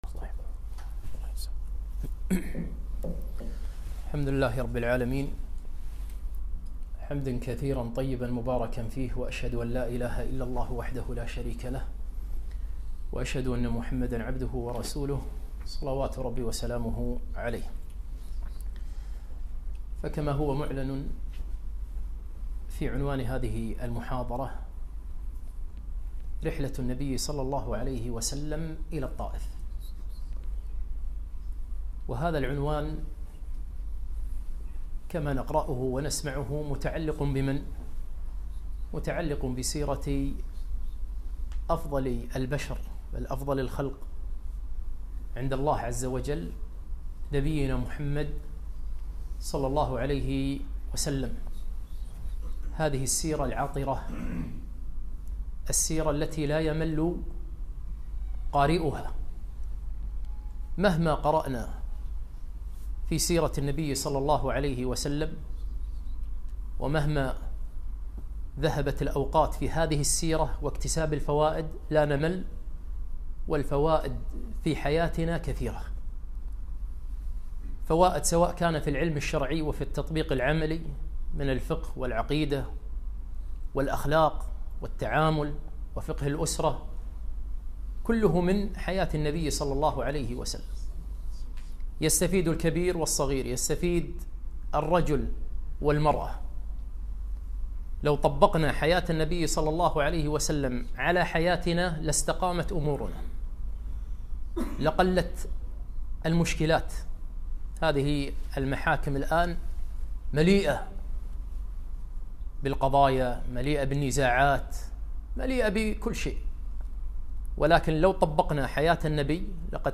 محاضرة - رحلة الرسول صلى الله عليه وسلم إلى الطائف